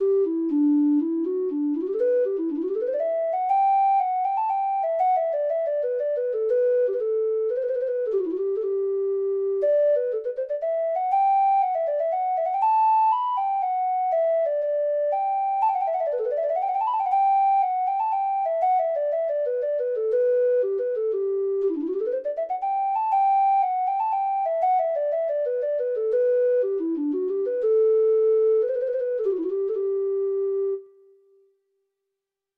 Treble Clef Instrument Traditional Treble Clef Instrument Traditional Treble Clef Instrument Free Sheet Music The Dark Woman of the Glen (Irish Folk Song) (Ireland)
Traditional Music of unknown author.
Irish